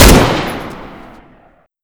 Index of /server/sound/weapons/dod_m1919
m249-1.wav